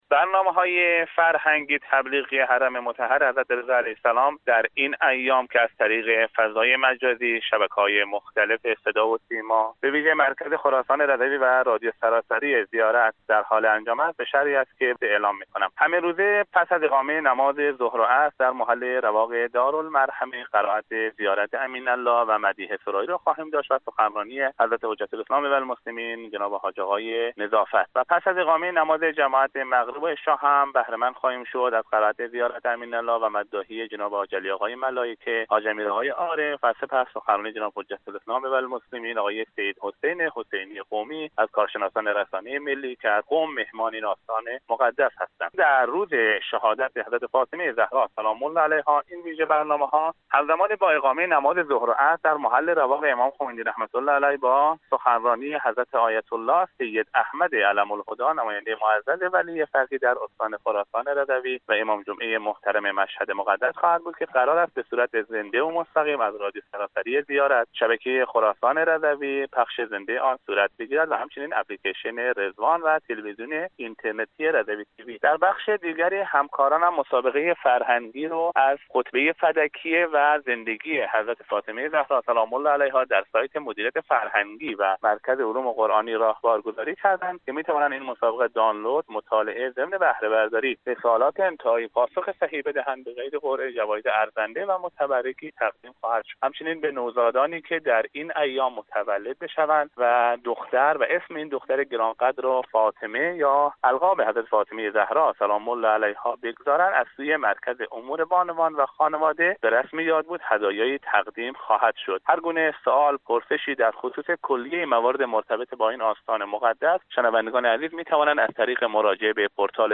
در گفتگو با خبر رادیو زیارت درباره ویژه برنامه‌های حرم امام رضا(ع) در ایام فاطمیه گفت: